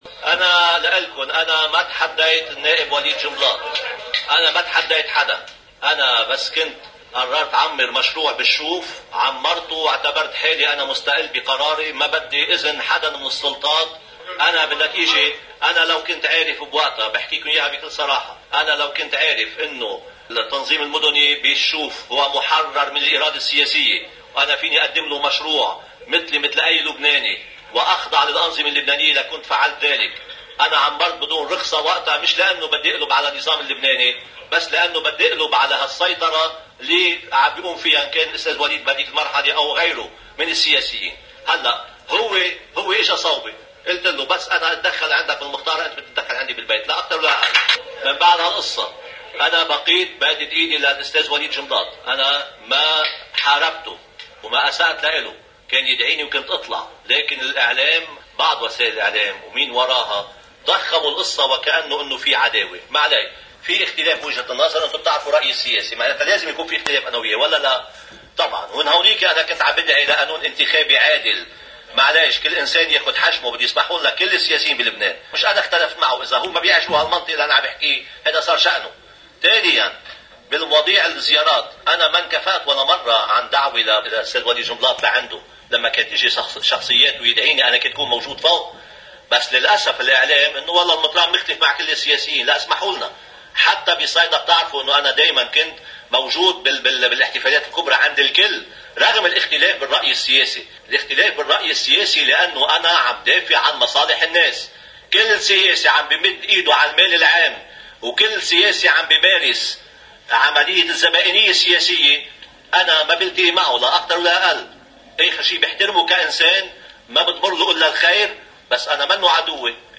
مقتطف من حديث المطران نصار في مؤتمر الصحفي: الجزء 5 والأخير: